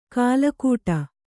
♪ kālakūṭa